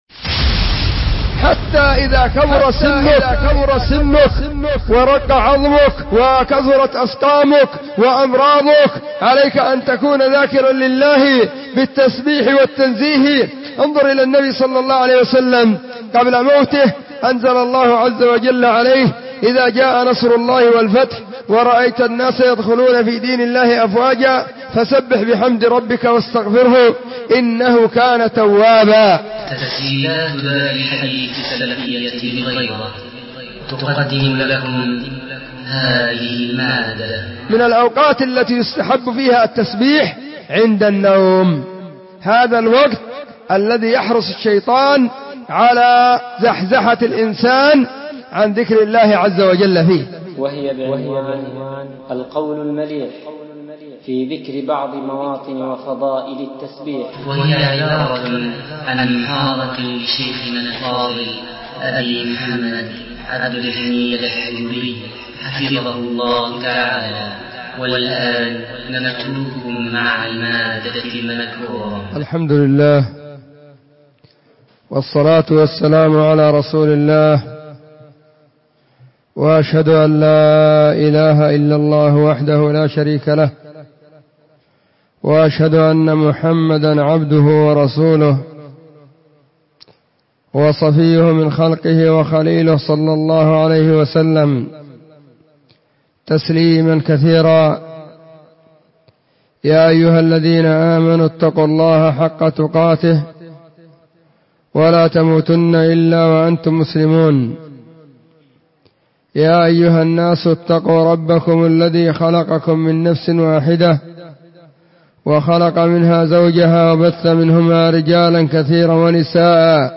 محاضرة بعنوان :القول المليح في ذكر بعض مواطن وفضائل التسبيح*
📢 مسجد الصحابة – بالغيضة – المهرة، اليمن حرسها الله،